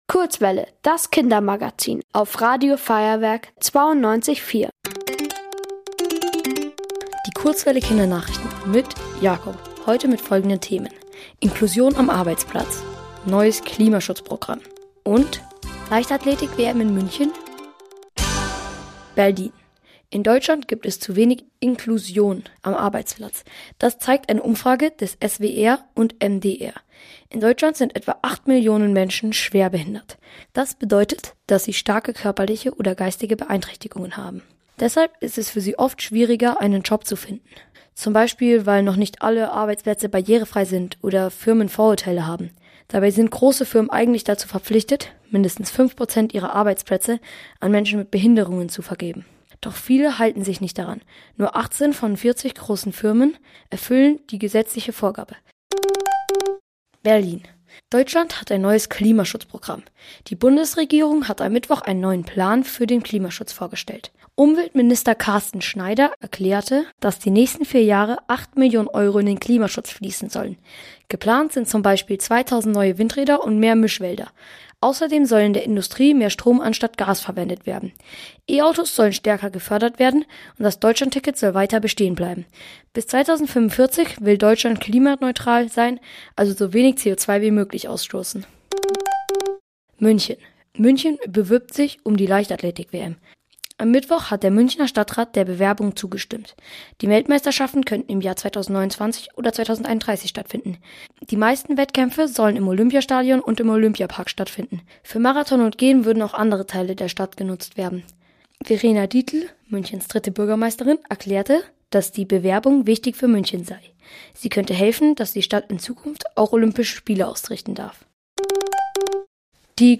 Die Kurzwelle Kindernachrichten vom 28.03.2026